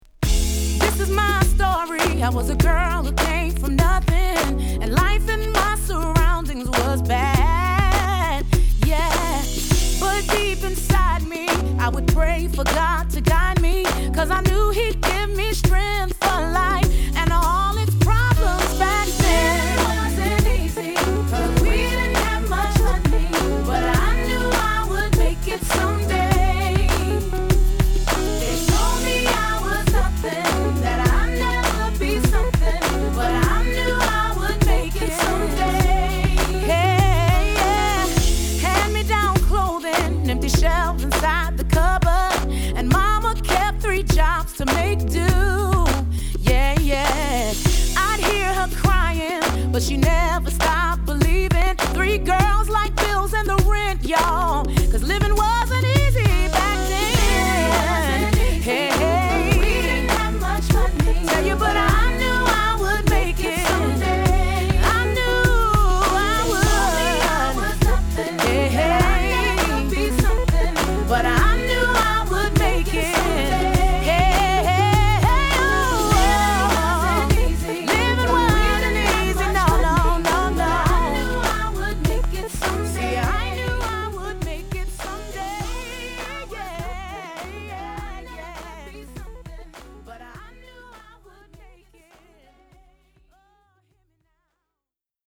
ピアノやギターのしっとりしたメロディに絡むネオ・ソウル路線のミディアムチューン！